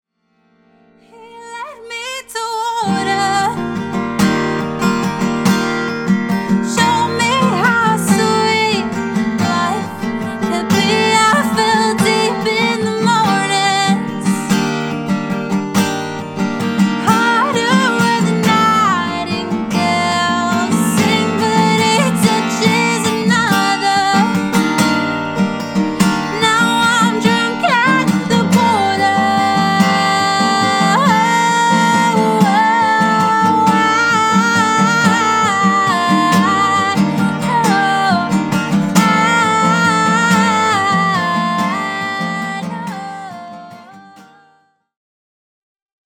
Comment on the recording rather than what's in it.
LIVE DEMO 2